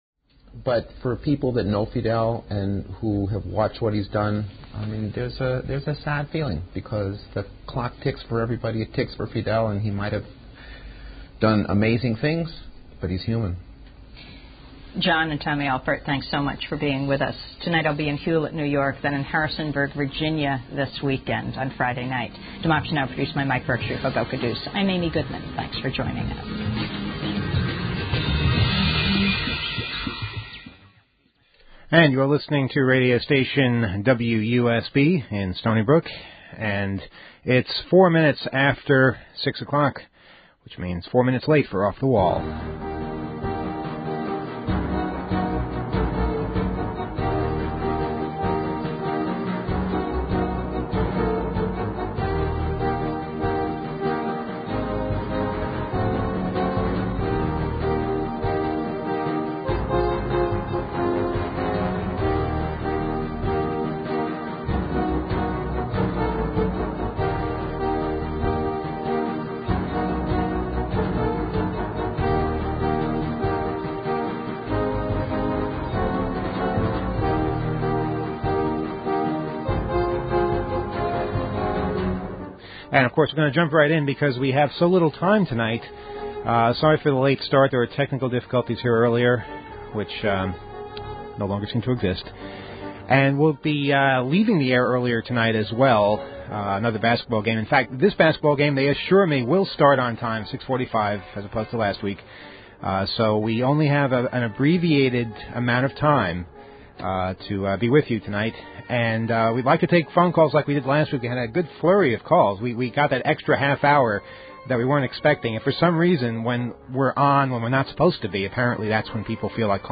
Some audio issues with the delay system, getting holiday greeting cards from corporations, Emmanuel reads some of the cards he's received, Emmanuel has a cell phone jammer, why overheard cell phone calls are more annoying than actual conversations